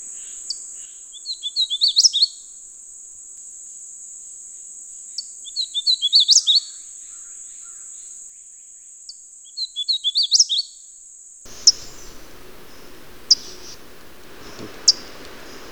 Hooded Warbler
Wilsonia citrina
The Hooded Warbler has a distinctive, loud chip note, which it frequently gives even in winter.